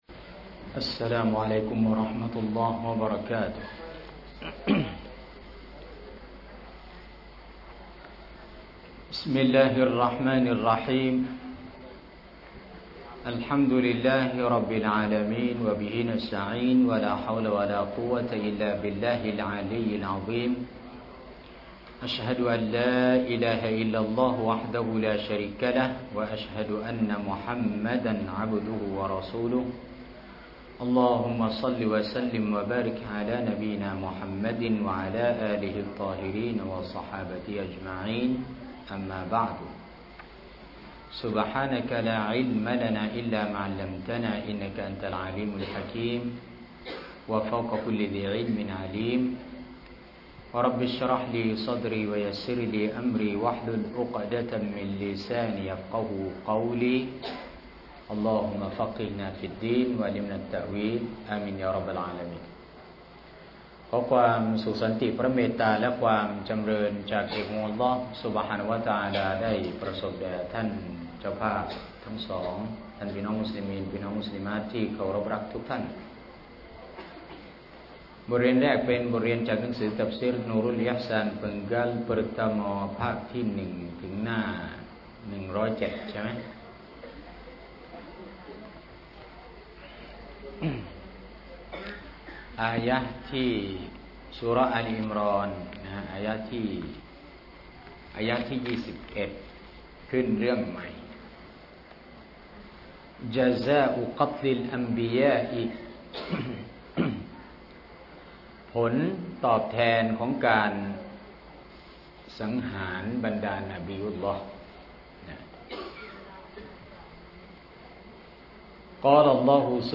สถานที่ : บ้านพนัสนาวรรณ อ.พนัสนิคม จ.ชลบุรี